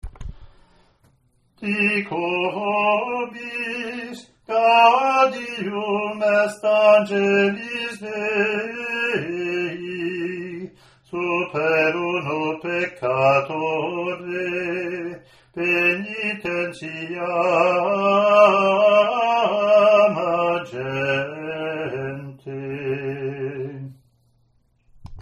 Latin antiphon (Year C) )
ot24c-comm-gm.mp3